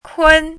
“昆”读音
kūn
昆字注音：ㄎㄨㄣ
国际音标：kʰuən˥